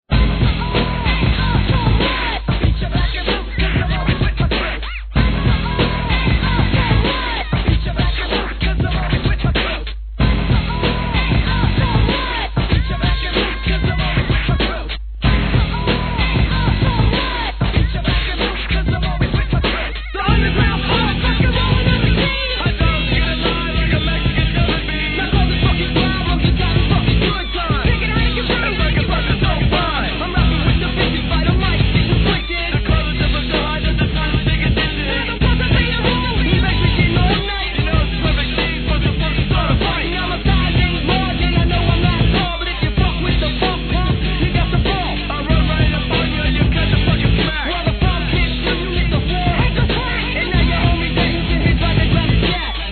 HIP HOP/R&B
ROCK X HIP HOPなアングラ物!!